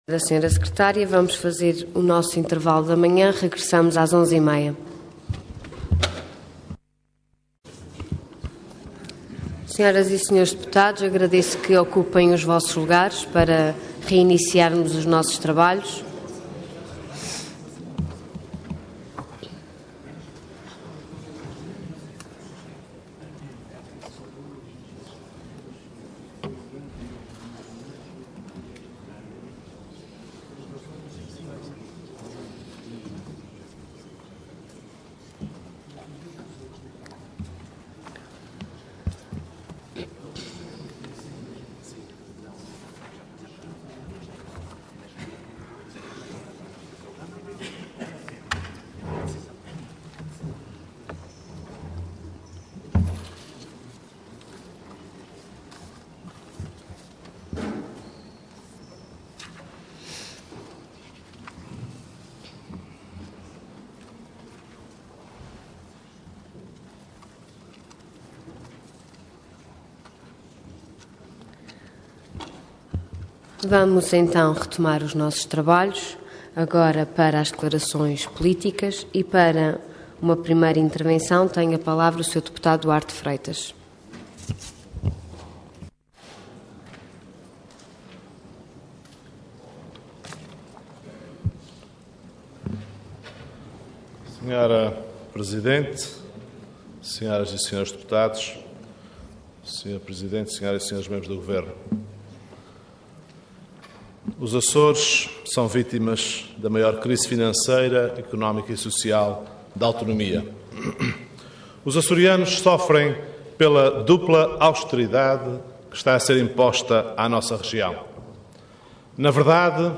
Parlamento online - Intervenção do Deputado Duarte Freitas do PSD - Declaração Política.
Detalhe de vídeo 16 de abril de 2013 Download áudio Download vídeo Diário da Sessão Processo X Legislatura Intervenção do Deputado Duarte Freitas do PSD - Declaração Política.